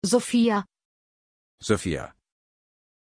Prononciation de Sophia
pronunciation-sophia-de.mp3